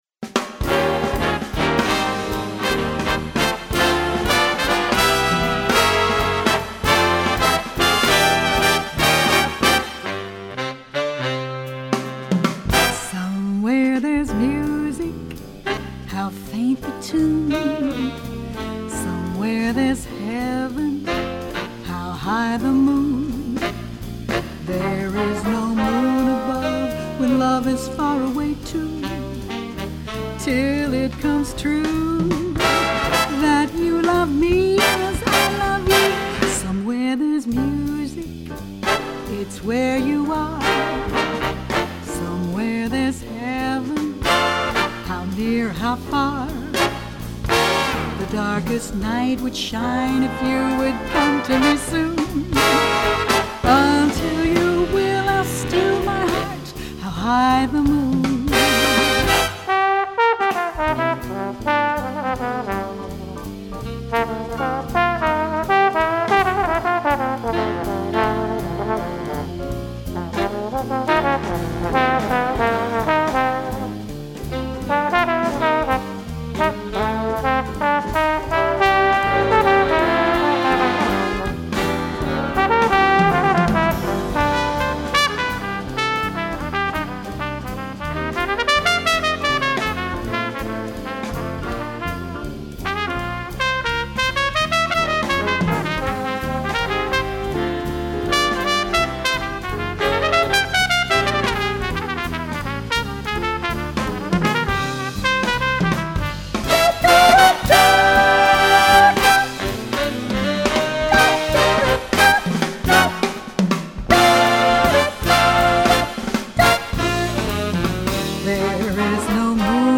Voicing: Big Band with Vocal